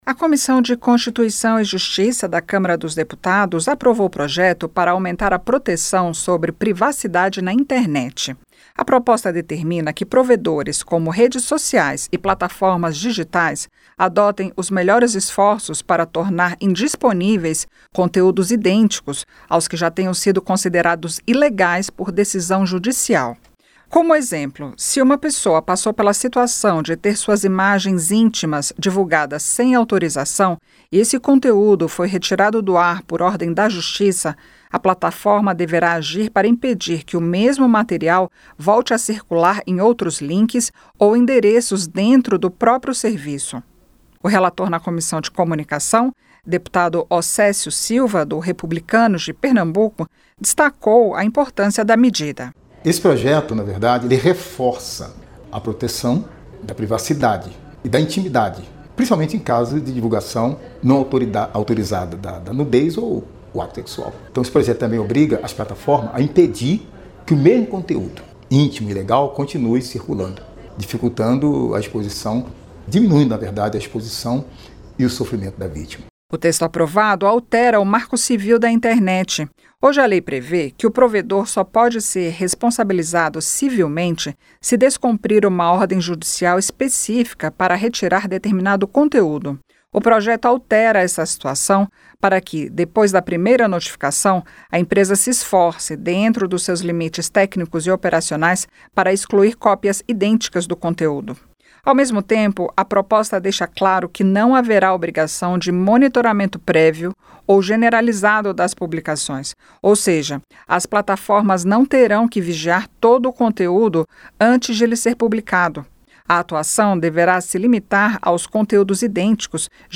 CÂMARA APROVA PROJETO QUE OBRIGA PLATAFORMAS NA INTERNET A ADOTAR MEDIDAS PARA RETIRAR CONTEÚDOS IDÊNTICOS AOS JÁ CONSIDERADOS ILEGAIS EM DECISÃO JUDICIAL. A REPÓRTER